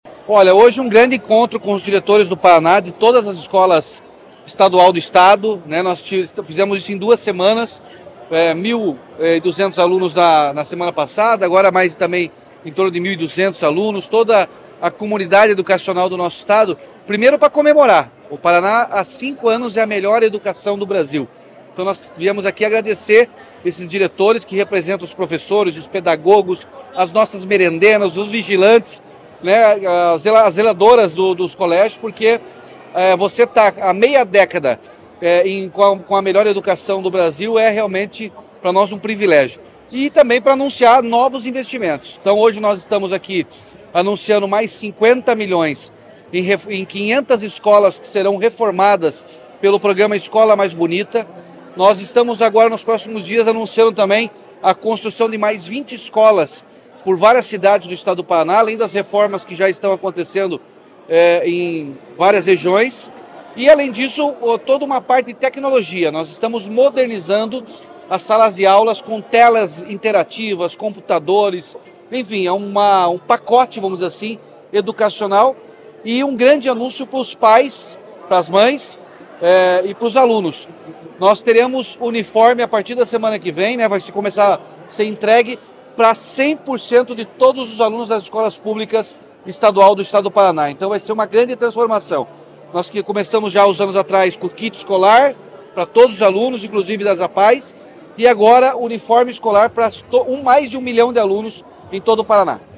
Sonora do governador Ratinho Junior sobre o pacote de investimentos e uniformes para toda a rede estadual de educação